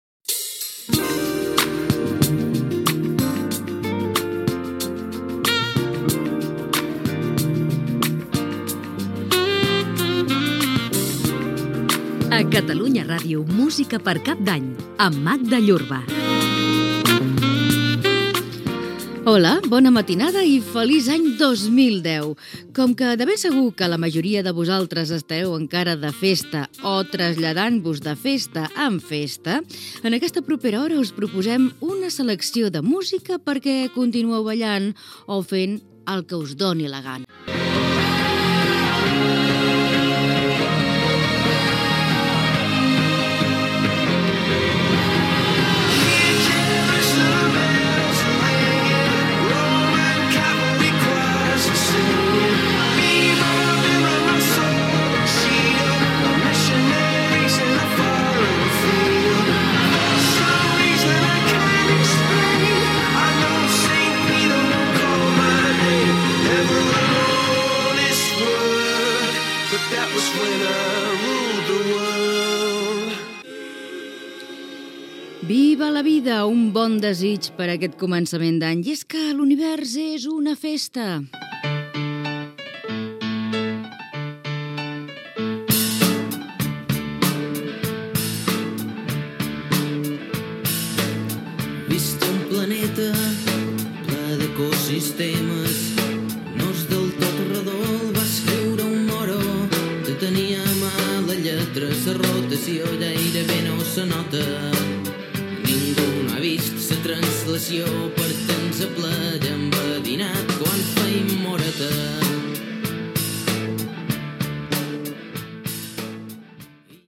Careta del programa, benvinguda i presentació d'un tema musical
Musical